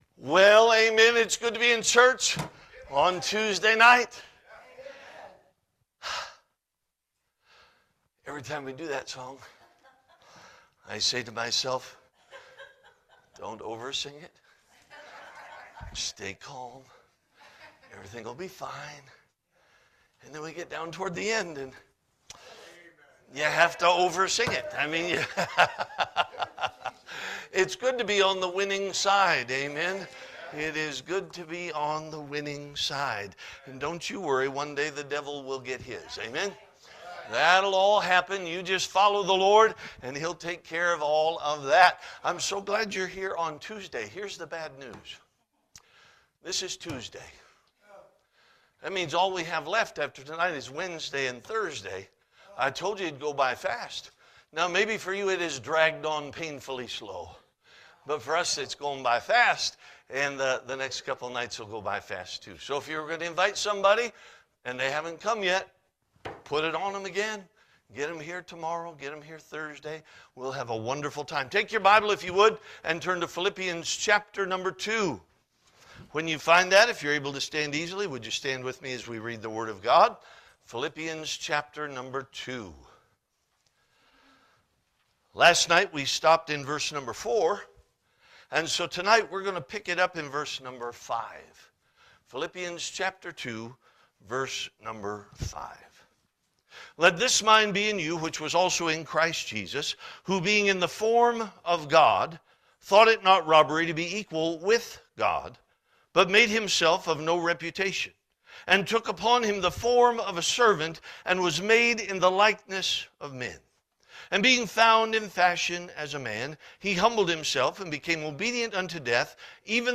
Spring Revival 2026 Passage: Philippians 2 Service Type: Revival « Spring Revival 2026